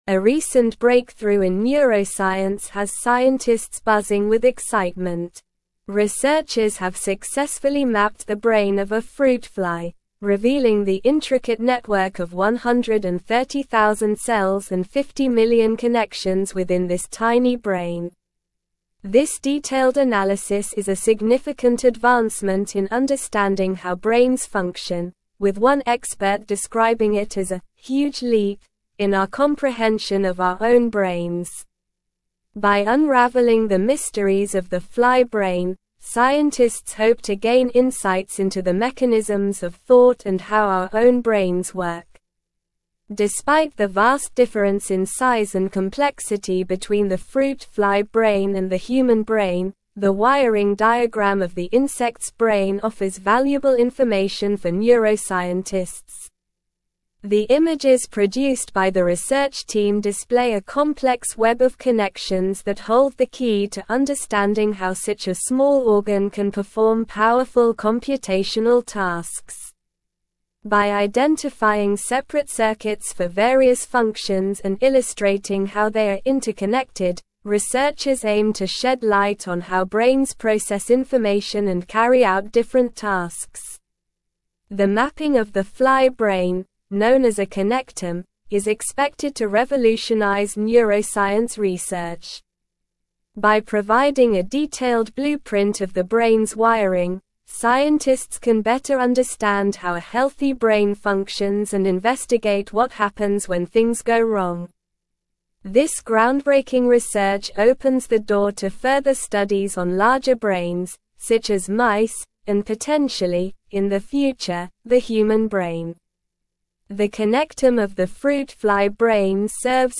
Slow
English-Newsroom-Advanced-SLOW-Reading-Groundbreaking-Neuroscience-Discovery-Mapping-a-Flys-Brain-Connections.mp3